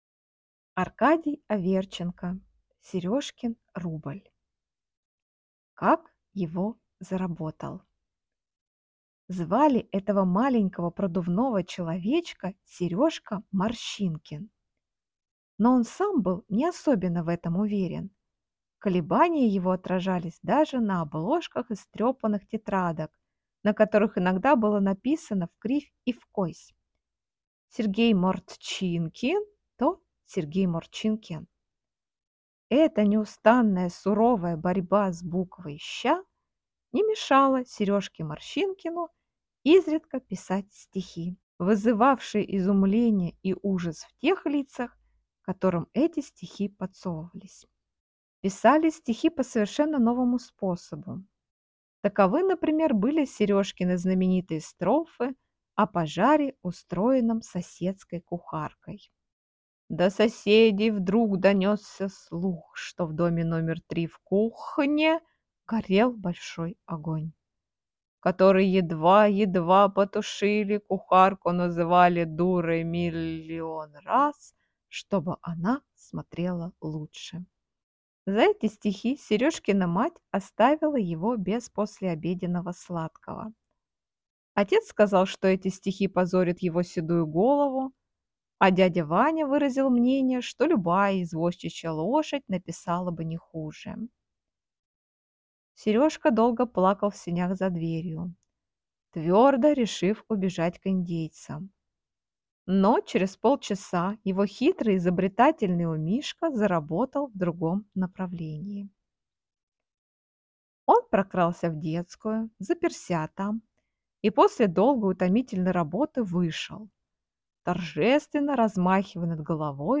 Аудиокнига Сережкин рубль | Библиотека аудиокниг